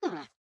Minecraft Version Minecraft Version 1.21.4 Latest Release | Latest Snapshot 1.21.4 / assets / minecraft / sounds / mob / armadillo / ambient6.ogg Compare With Compare With Latest Release | Latest Snapshot